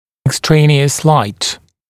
[ɪk’streɪnɪəs laɪt] [ek-][ик’стрэйниоэс лайт] [эк-]посторонний свет